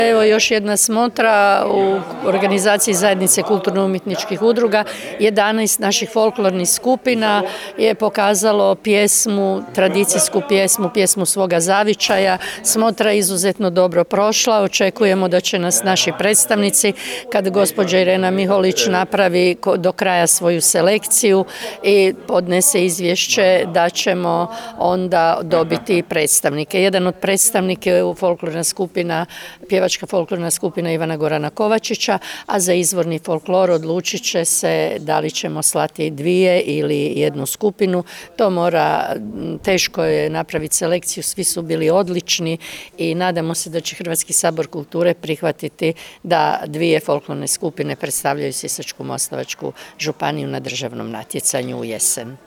U organizaciji Zajednice kulturno-umjetničkih udruga SMŽ i uz potporu Ministarstva kulture i medija i Sisačko-moslavačke županije u Županijskom centru s kongresnom dvoranom u Sisku održana je Smotra malih vokalnih sastava SMŽ.